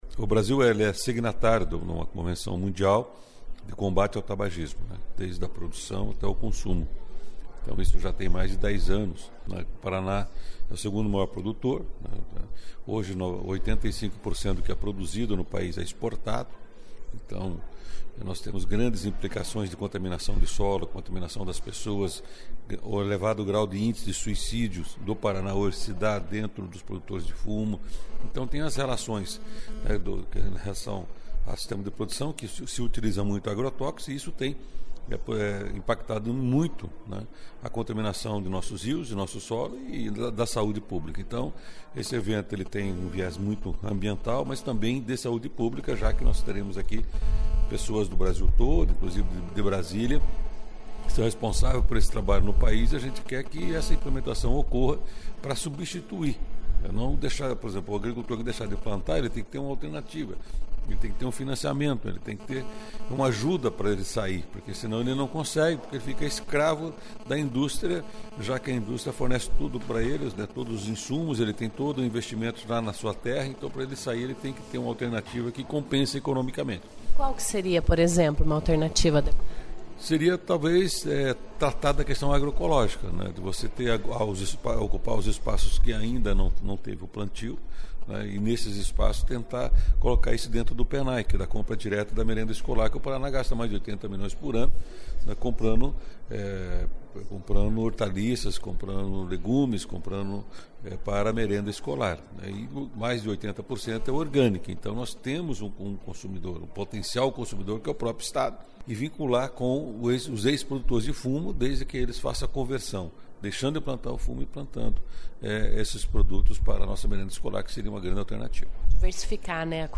Também foram apresentados resultados de projetos já implemntados em outros estados e que deram certo. Ouça a entrevista do parlamentar.